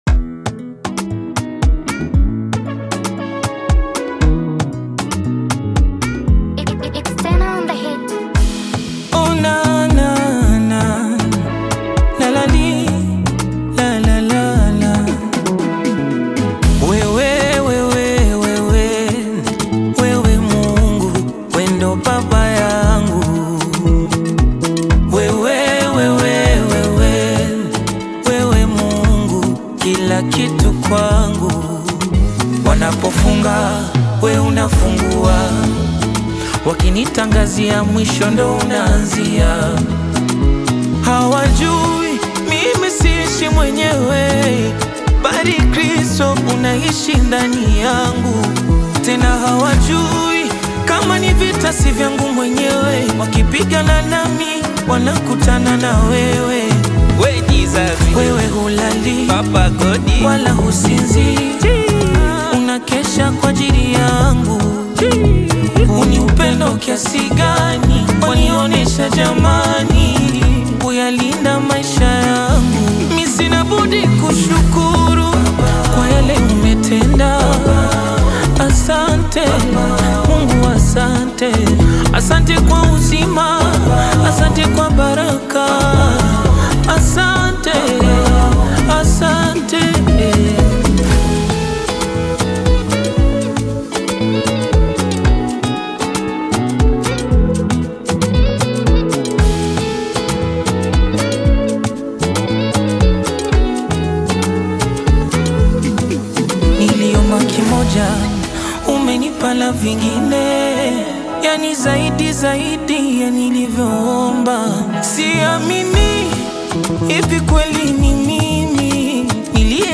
Tanzanian gospel
soulful gospel song
Through emotional vocals and spiritually uplifting lyrics